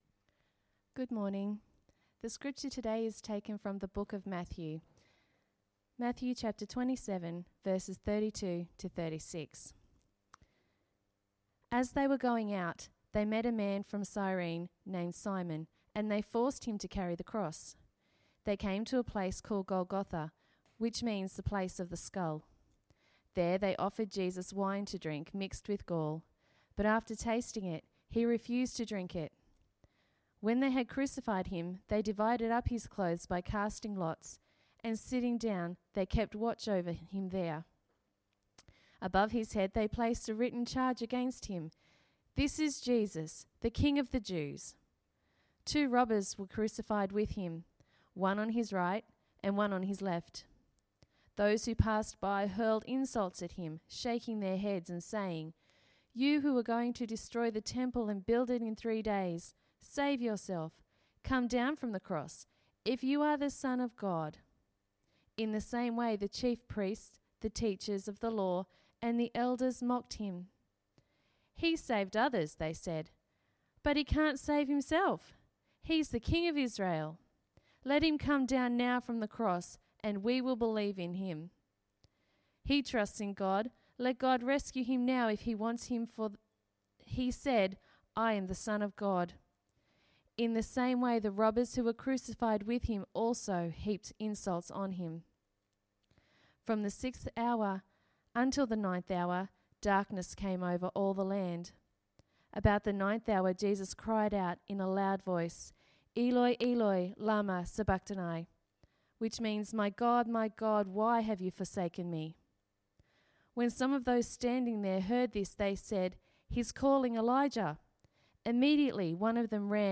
Sermon- Good Friday
Sermon_-_Good_Friday.mp3